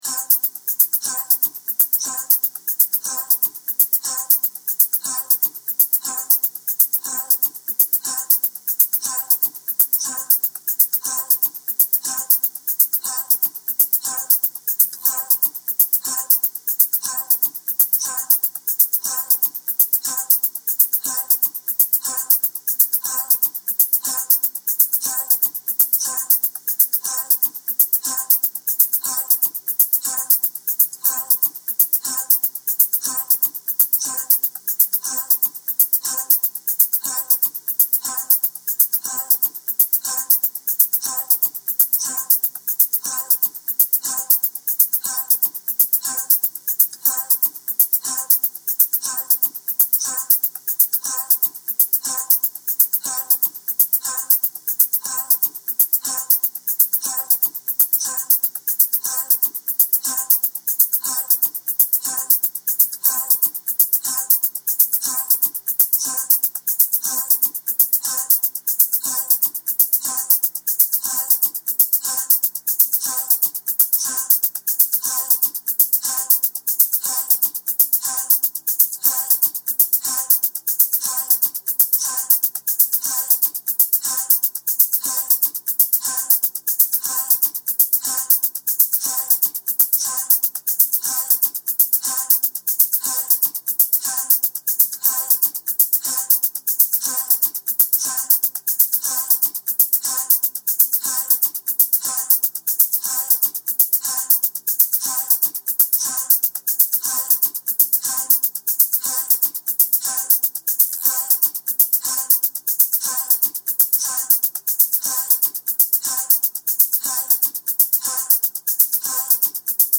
Or use the audio file to play to easily transition into each posture: (timed out 3 min, 3 min, 3 min, 1 min, 1 min, 1 min, 3 min.)
Invoking-the-Wealth-of-the-Universe-music-.mp3